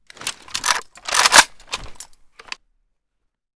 ak74_unjam.ogg